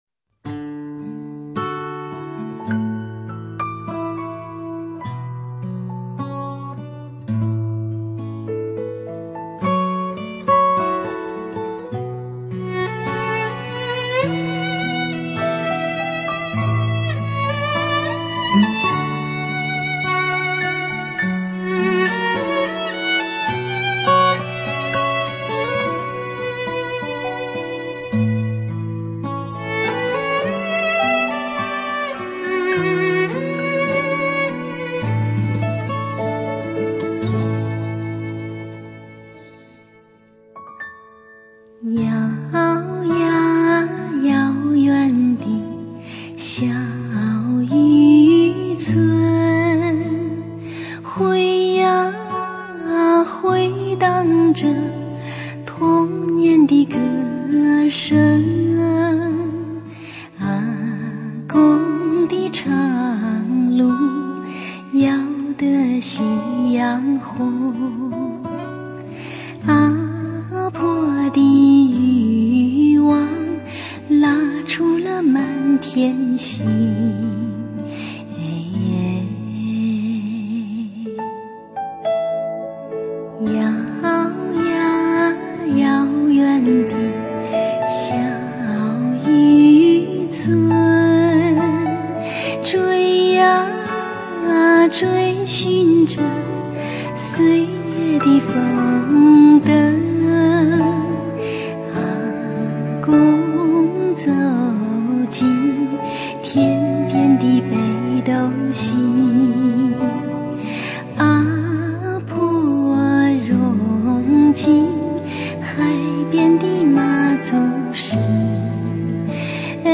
女声独唱